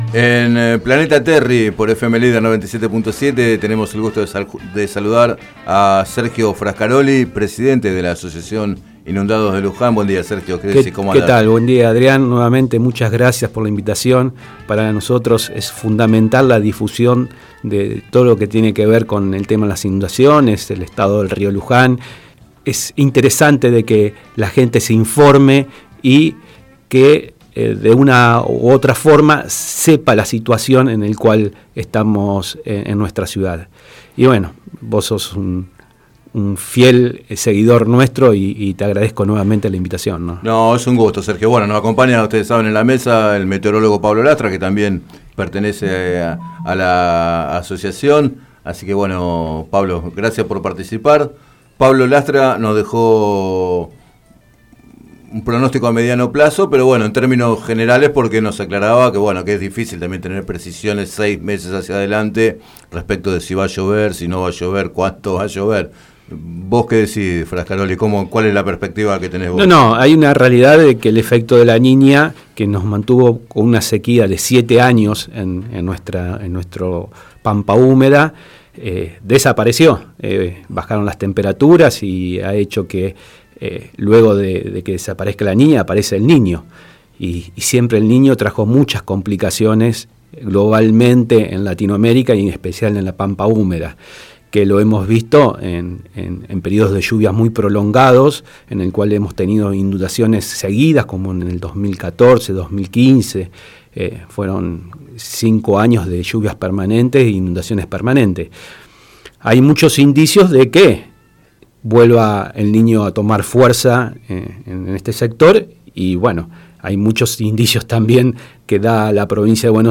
Entrevistados en el programa Planeta Terri de FM Líder